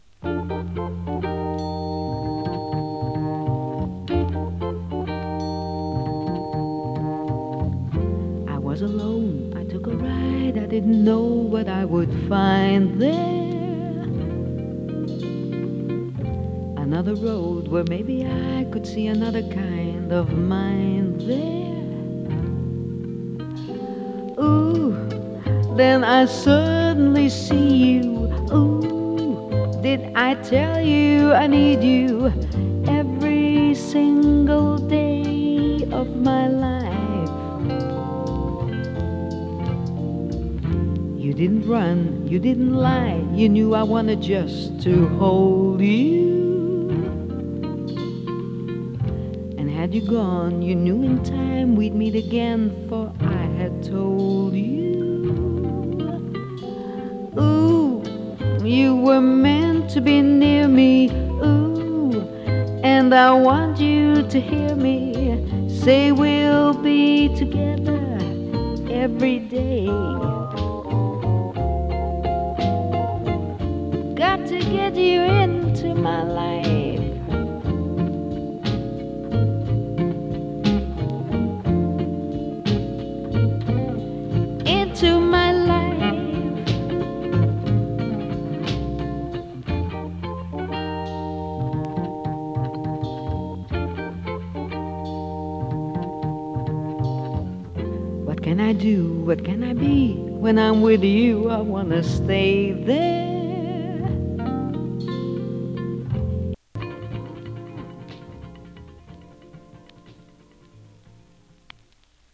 ラウンジ ジャズボーカル
オランダを代表する女性ジャズ・シンガー。